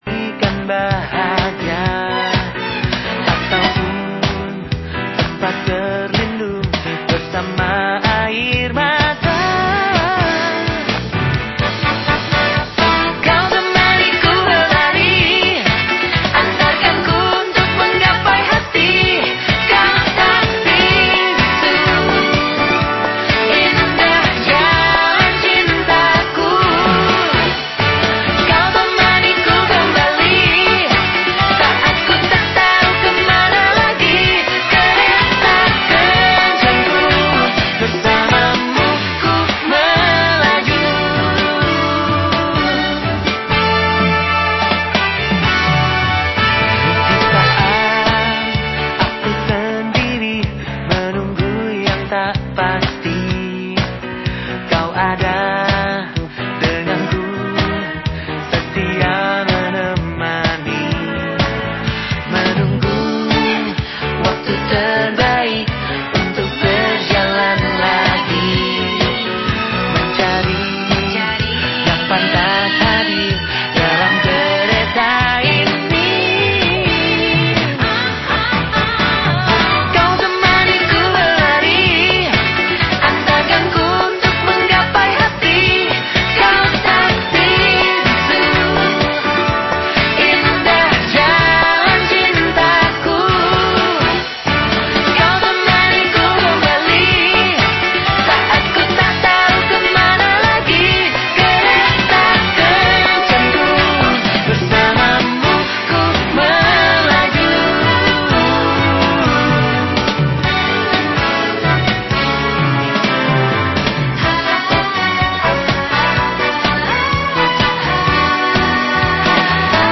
DIALOG LINTAS BANDUNG PAGI PRO 1 BANDUNG/TOPIK : PERAN BANK INDONESIA DALAM PEMULIHAN EKONOMI DI JAWA BARAT. 04 JULI 2022 | PPID LPP RRI